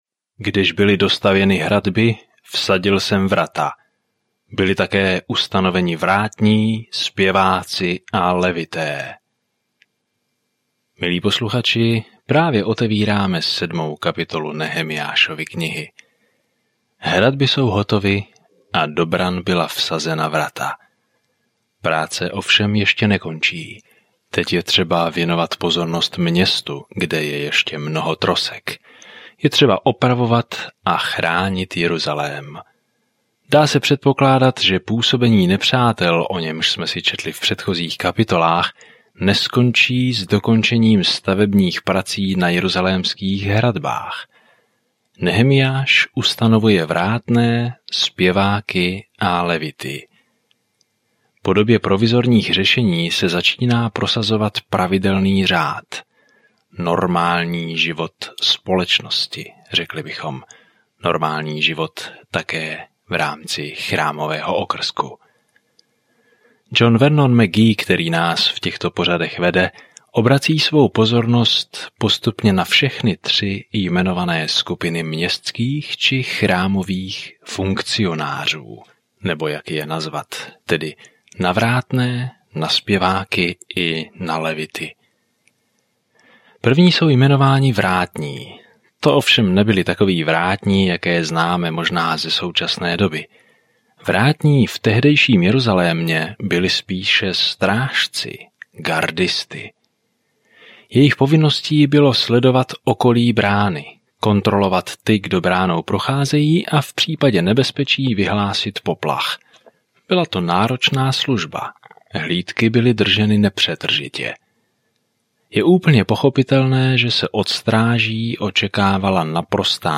Písmo Nehemiáš 7:1-7 Den 12 Začít tento plán Den 14 O tomto plánu Když se Izrael vrátí do své země, Jeruzalém je ve špatném stavu; obyčejný muž, Nehemiah, v této poslední historické knize znovu staví zeď kolem města. Denně procházejte Nehemiášem a poslouchejte audiostudii a čtěte vybrané verše z Božího slova.